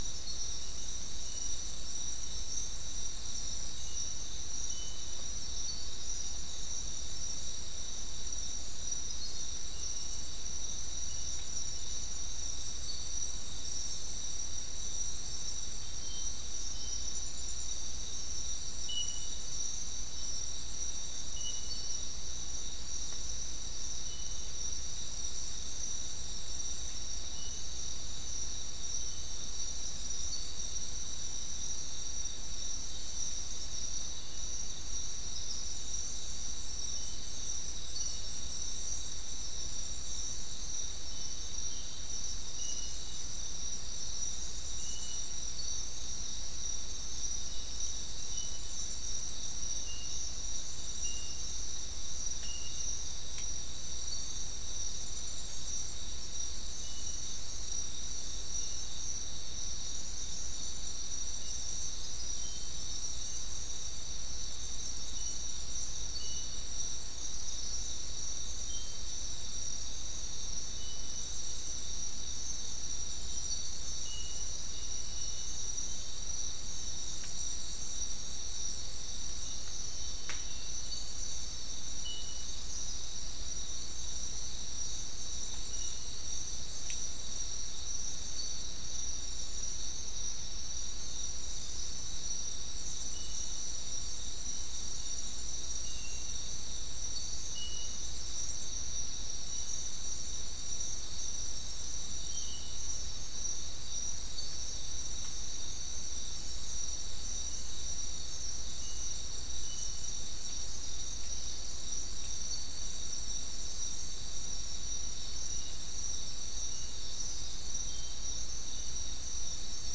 Non-specimen recording: Soundscape Recording Location: South America: Guyana: Mill Site: 4
Recorder: SM3